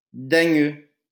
Dagneux (French pronunciation: [daɲø]